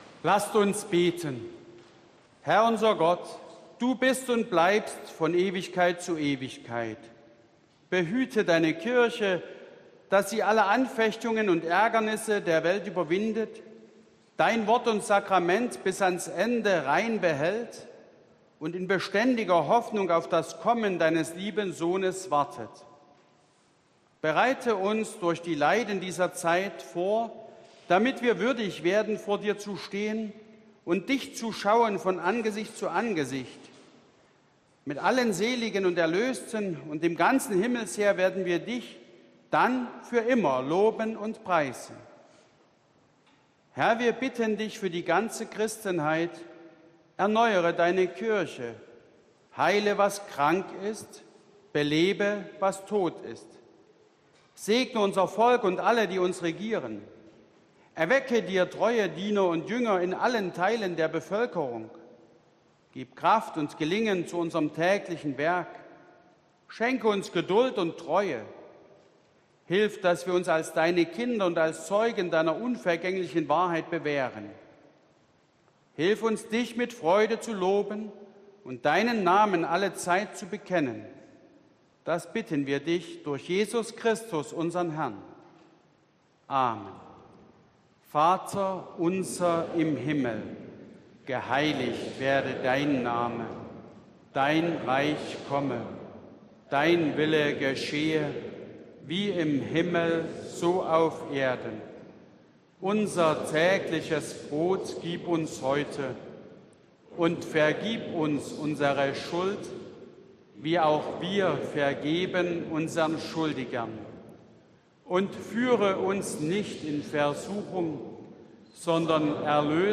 Audiomitschnitt unseres Gottesdienstes vom Vorletzten Sonntag im Kirchenjahr 2022.